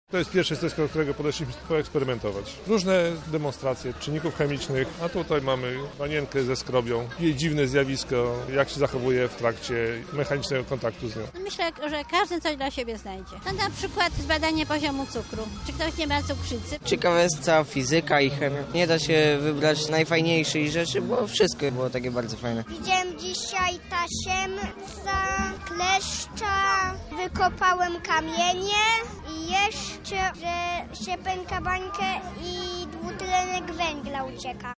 Nasza reporterka sprawdziła, co najbardziej przyciągnęło uwagę mieszkańców Lublina.
Piknik odbył się wczoraj na Arenie Lublin w ramach festiwalu Nauki, który potrwa jeszcze do piątku.